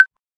pause-retry-click.ogg